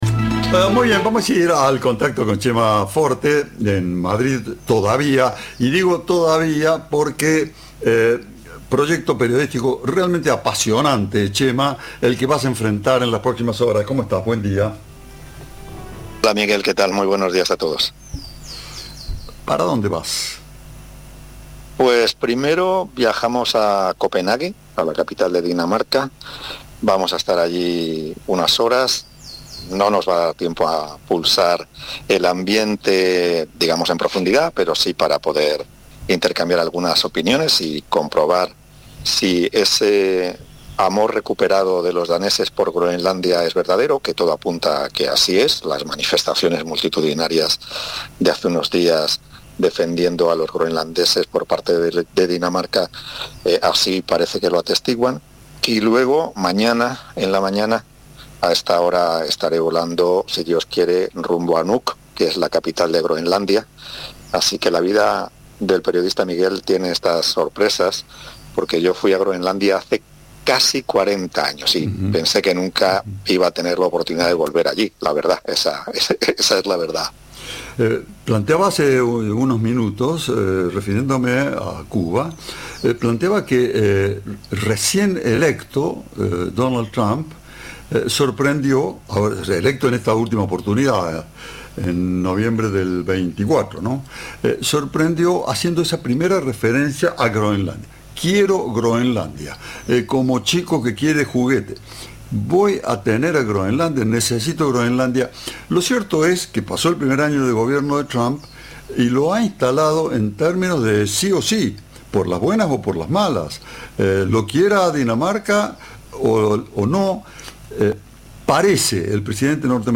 En diálogo con Cadena 3, el dibujante compartió anécdotas y reflexiones sobre el creador de Mafalda.